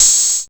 Drums10C.wav